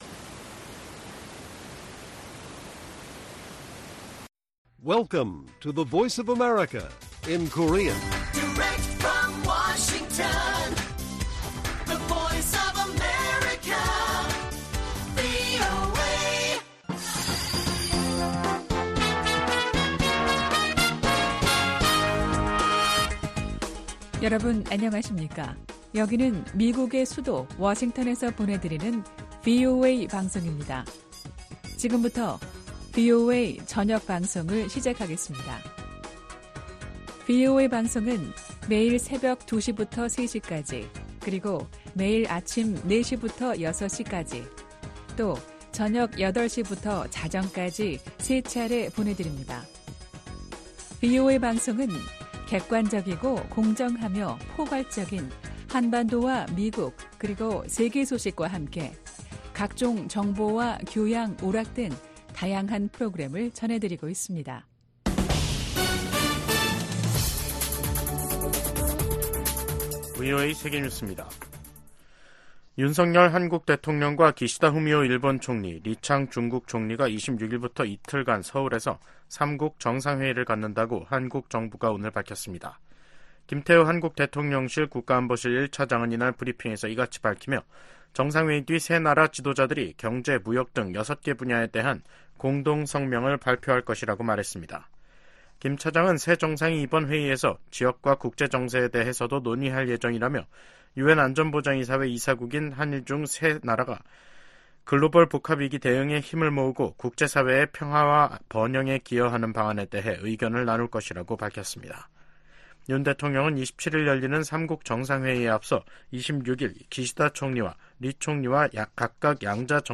VOA 한국어 간판 뉴스 프로그램 '뉴스 투데이', 2024년 5월 23일 1부 방송입니다. 토니 블링컨 국무장관은, 미국이 한국, 일본과 전례 없는 방식으로 공조하고 있다고 하원 청문회에서 증언했습니다. 북한이 러시아에 군수품을 제공하면서 러시아가 무기 생산을 확대할 기회를 마련해줬으며, 러시아는 그 대가로 북한에 기술을 제공할 수 있다고 미국 합참의장이 평가했습니다. 미국 와이오밍주가 북한과 연계된 회사 3곳에 폐쇄 조치를 내렸습니다.